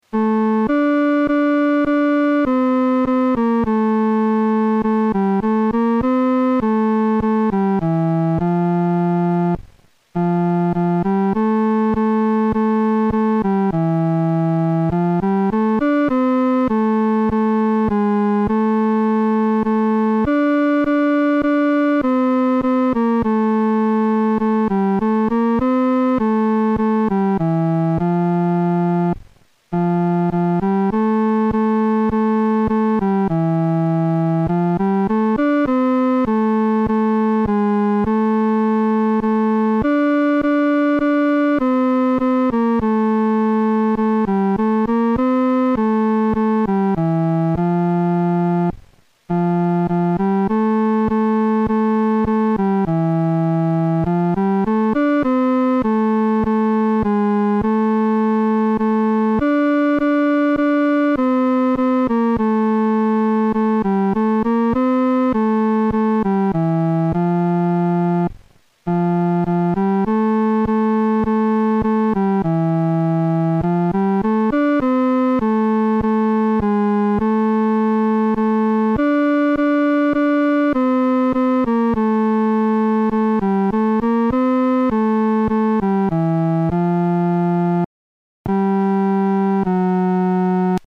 伴奏
男高
这首诗歌宜用不太慢的中速弹唱。